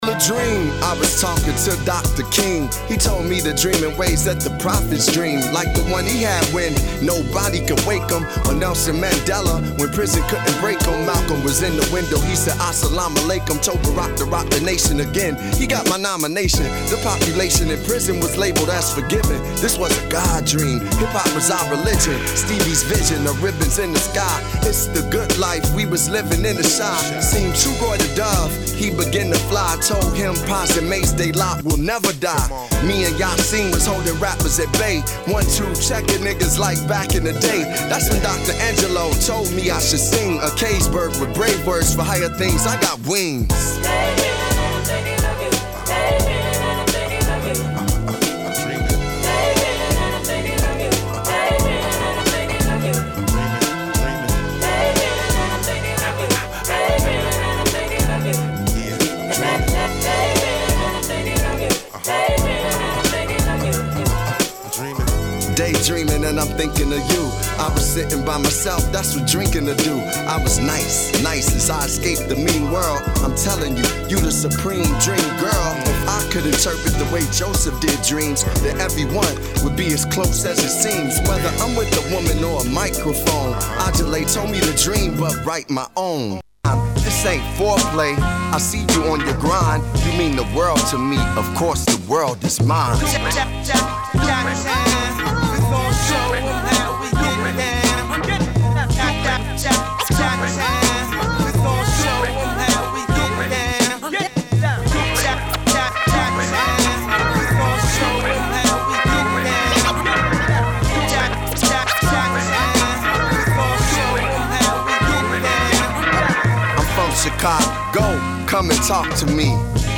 Hiphop-Breakbeat